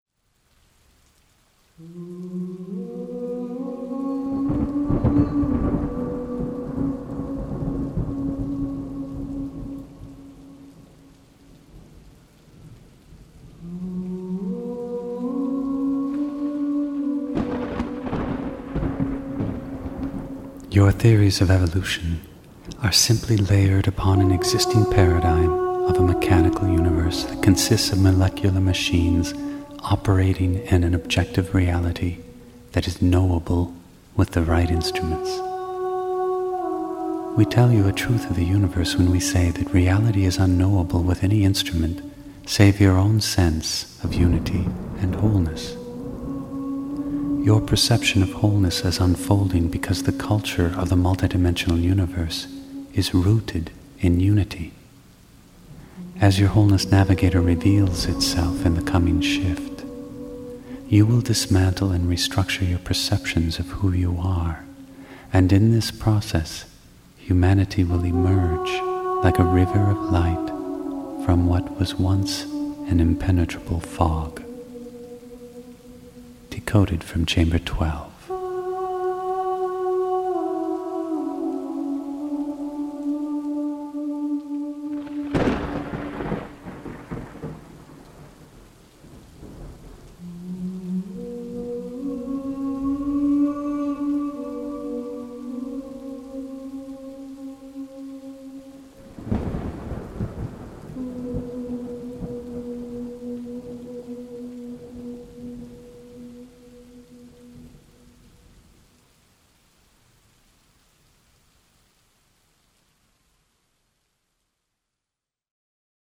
Filosofia Inediti – Audiolettura